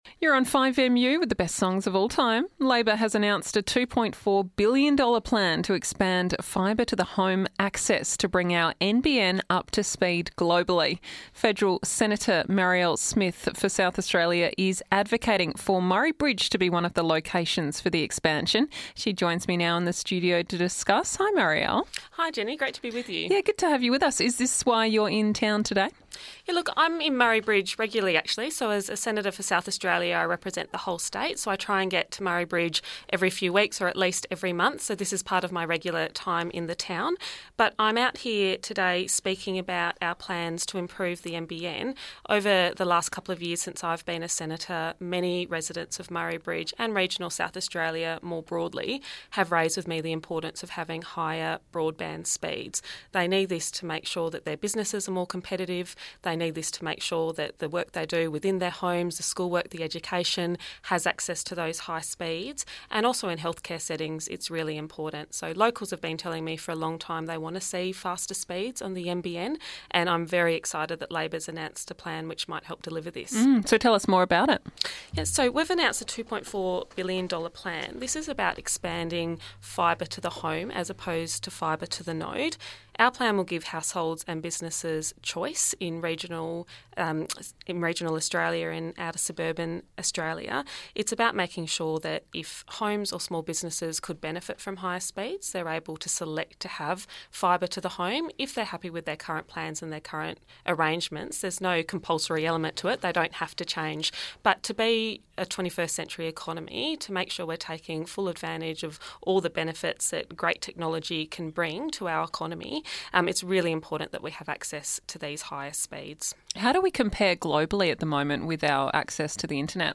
in studio for a chat about the plan, the need for more